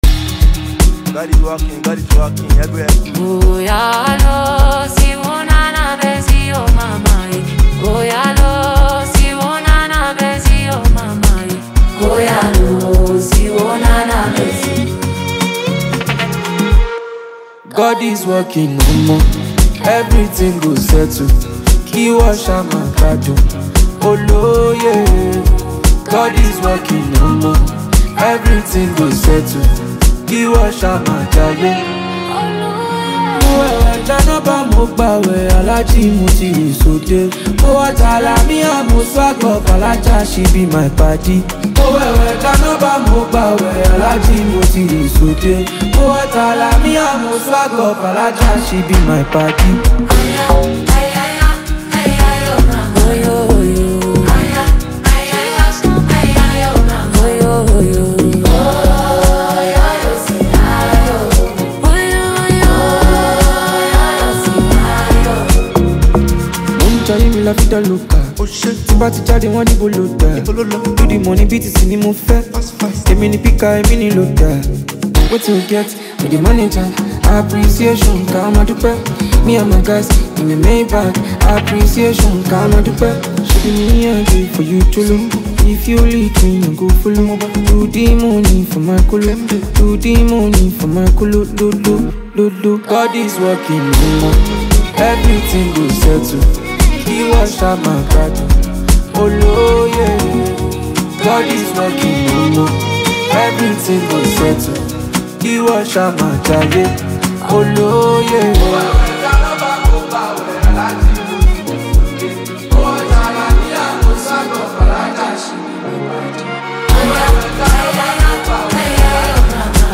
adding to his growing discography of soulful music.
soulful sound